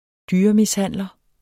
Udtale [ -misˌhanˀlʌ ]